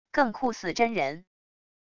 更酷似真人wav音频